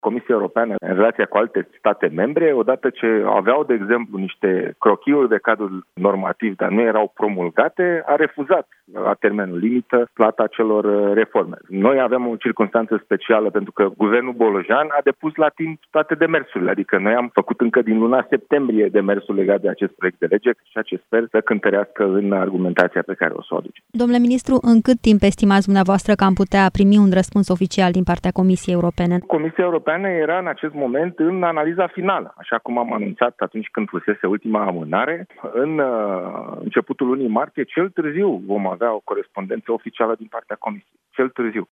Ministrul Fondurilor Europene, Dragoș Pîslaru: „ Noi avem o circumstanță specială, pentru că Guvernul Ilie Bolojan a depus la timp toate demersurile”